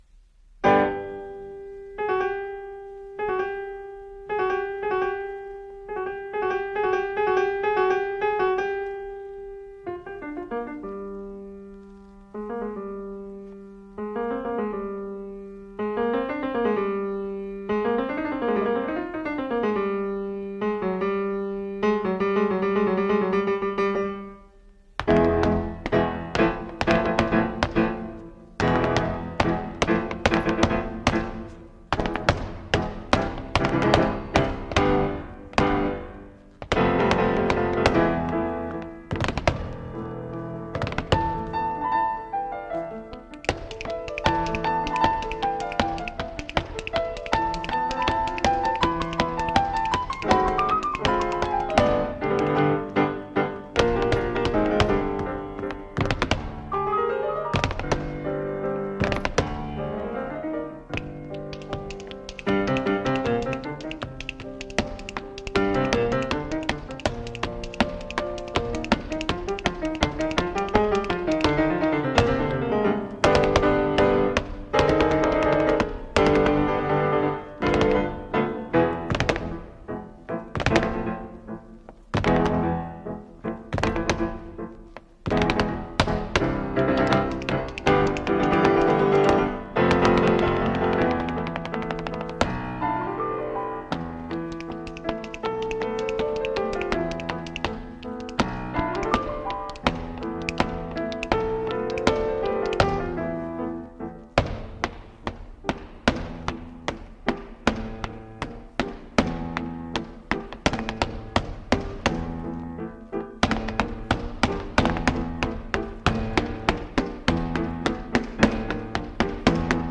chant et danse
piano